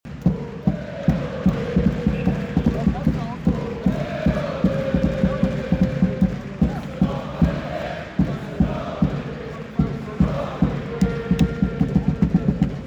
Wie bei nahezu jeder Teamsportart dürfen auch beim Eishockey die Fangesänge nicht fehlen. Wir haben euch die beliebtesten aus dieser Saison zusammengestellt.